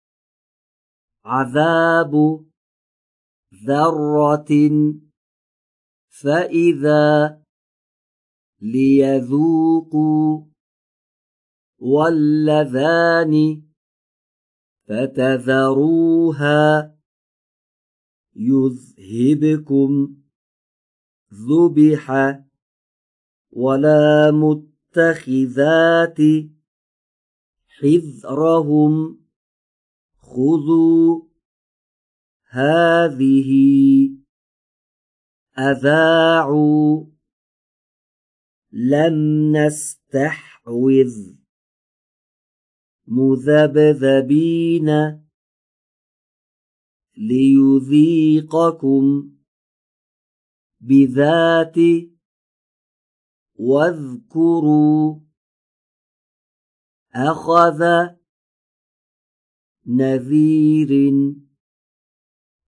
۴- فاصله بین سقف و سطح دهان را کم کنید تا آن حرف نازک تلفظ شود.
🔸ابتدا به تلفظ حرف «ذ» در این کلمات گوش فرا دهید و سپس آنها را تکرار کنید.